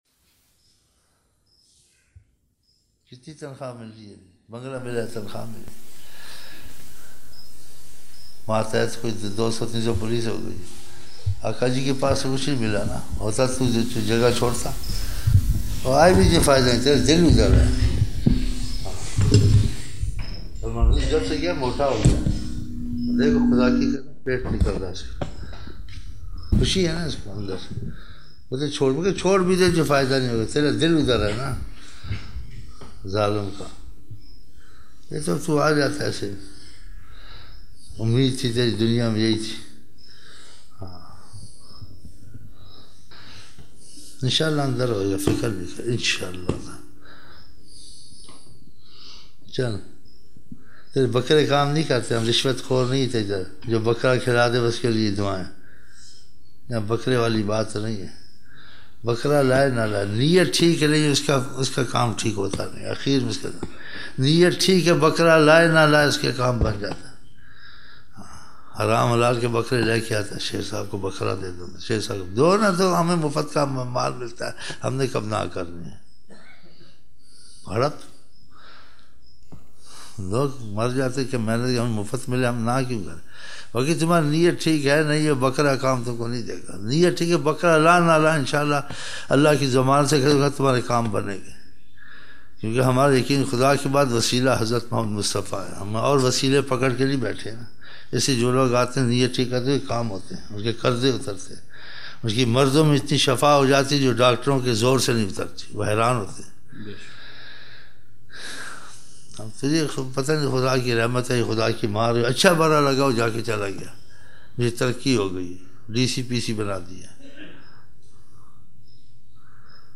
23 November 1999 - Fajar mehfil (15 Shaban 1420)
Hamara waseela Hazrat Muhammad saw hain, dil niyat Aaqa jee saw ke saath theek karlein, Aaqa jee saw ne kafroon ke mulk mai rehna pasand nahi farmaya, wazeefay parhnay se masail hal hotay hain, dunya se dil na lagao, ziada durood shareef parhnay wala Aaqa jee saw kay kareeb, daarhi hukman sunnat hai, naamaz achai aur burai ki taqeed, imaan mazboot ho to saray kaam ho jatay hain, yahan pe maafi ka rasta, deen eik aasaan rasta, sirf Khuda o Rasool saw ki raaza ke kaam karne hain, gungunaye ashaar (na kaleem ka tasawar) Naat shareef: